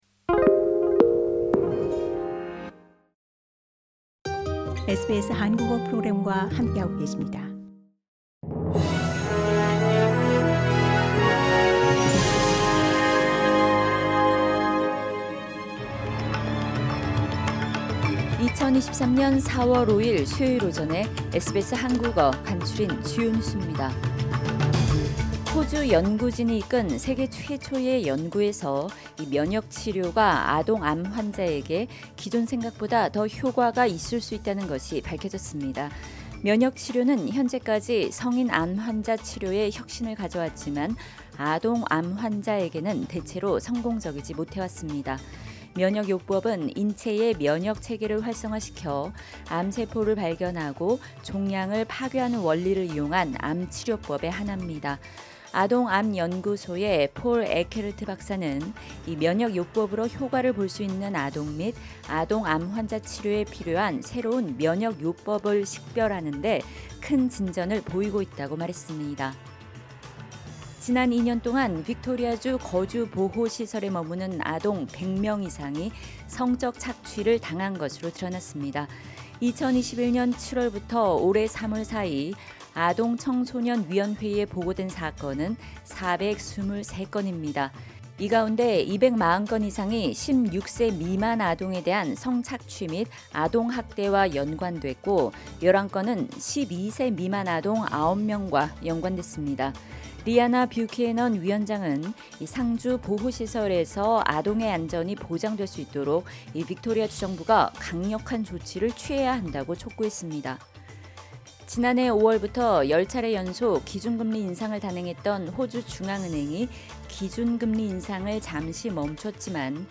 2023년 4월 5일 수요일 아침 SBS 한국어 간추린 주요 뉴스입니다